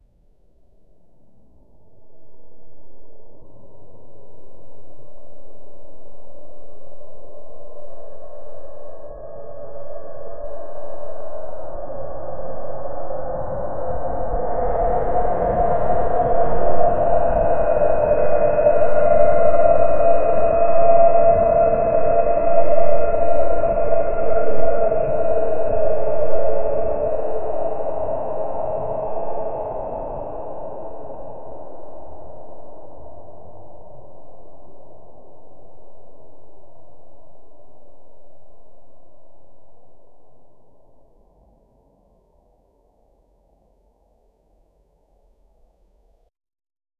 ambient-sounds-of-an-empt-inl5g25b.wav